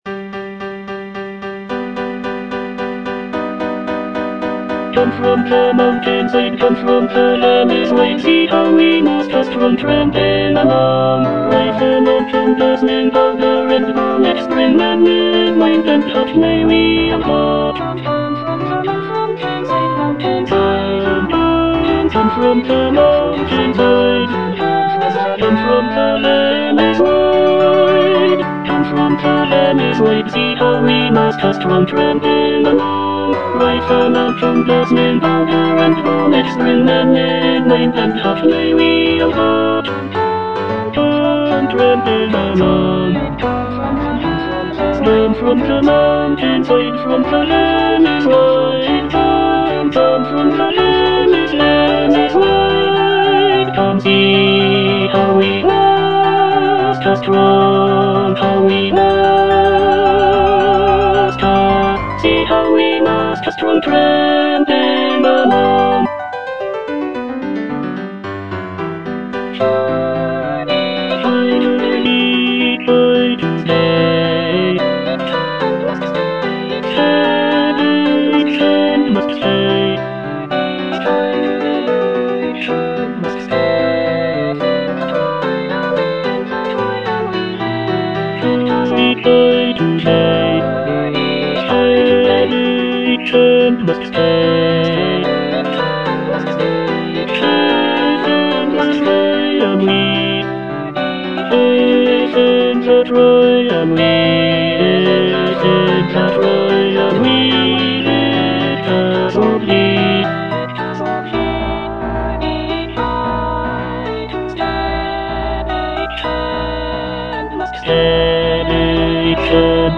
(tenor I) (Emphasised voice and other voices)